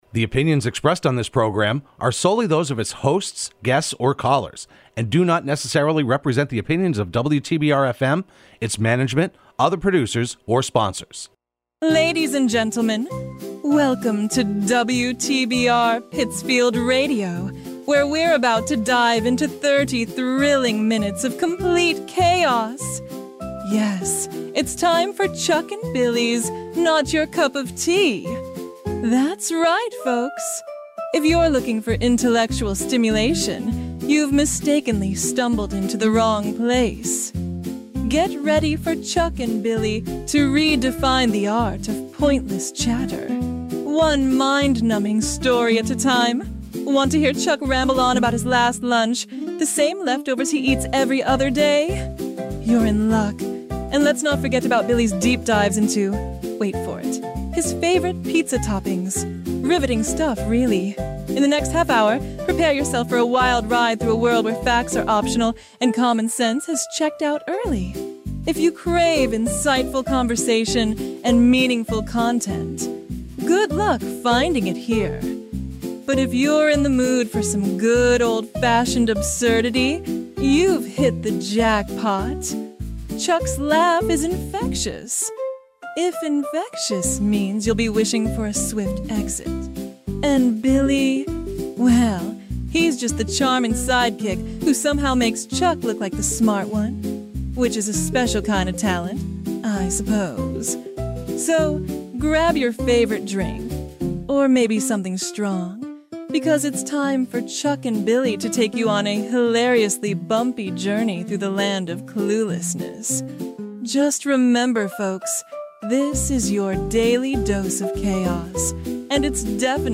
Broadcast live every Wednesday at 4pm on WTBR.